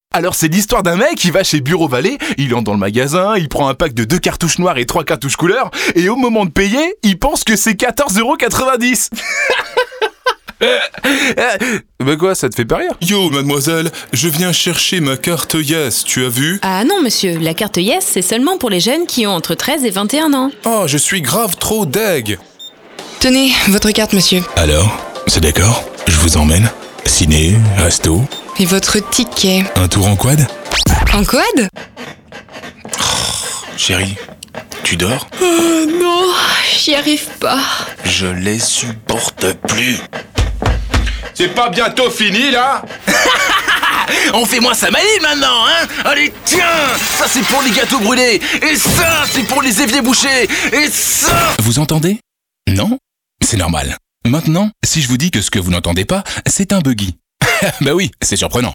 Voix off français grave posée jouée dynamique souriant home studio band annonce pub radio télé e learning
Sprechprobe: Sonstiges (Muttersprache):